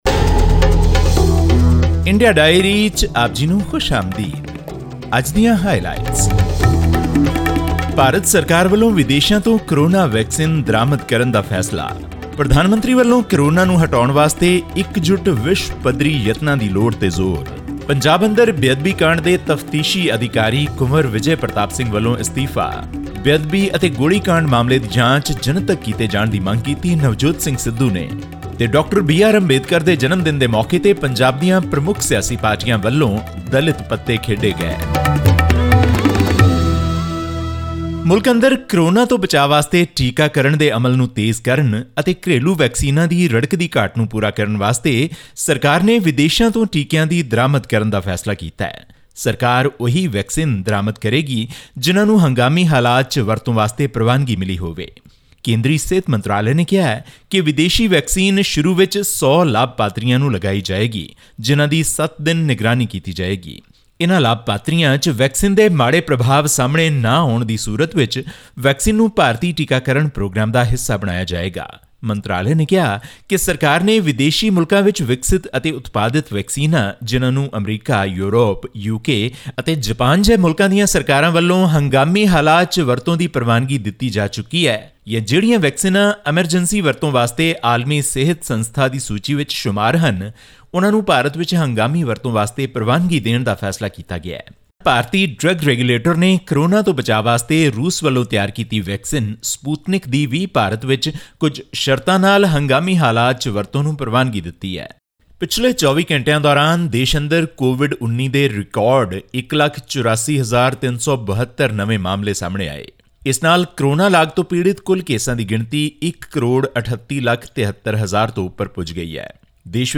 India on Wednesday said it would fast-track emergency approvals for foreign-produced COVID-19 vaccines that have been granted emergency authorisation in other countries, as the country continues to witness a record surge in daily infections. All this and more in our weekly news segment from India.